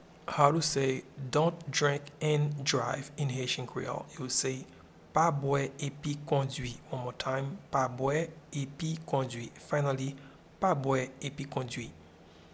Pronunciation and Transcript:
Dont-drink-and-drive-in-Haitian-Creole-Pa-bwe-epi-kondwi.mp3